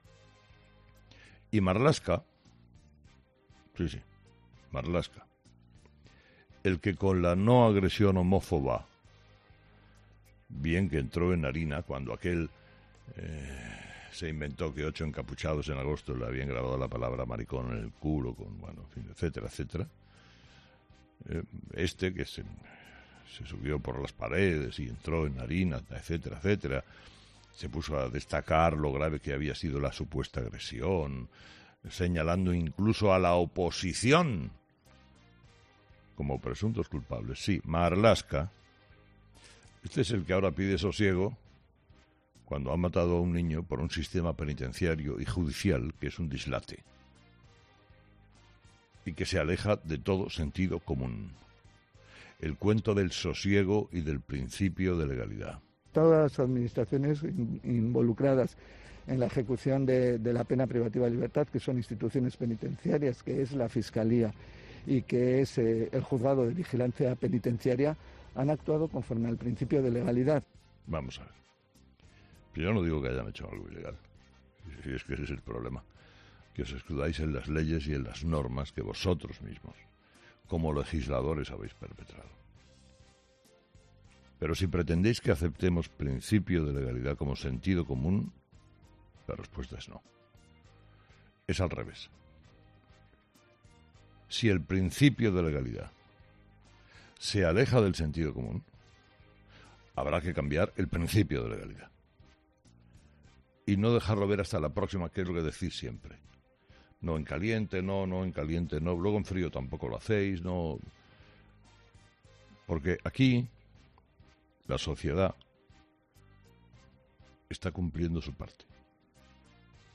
Carlos Herrera, director y presentador de 'Herrera en COPE', comenzaba el programa de ese martes analizando las principales claves de la jornada, que pasaban, entre otros asuntos, por la cumbre de la COP26 de Glasgow y la derogación o no de la reforma laboral.